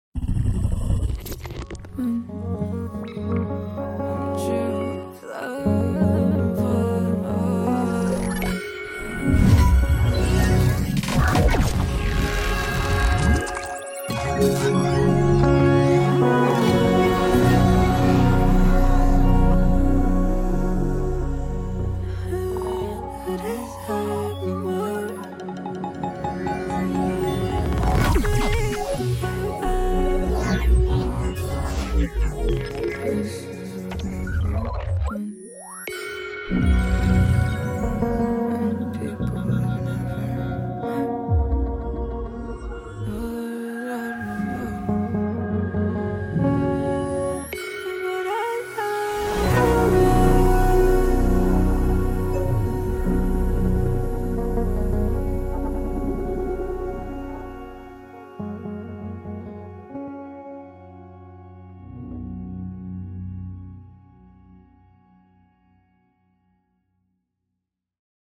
vocals & guitar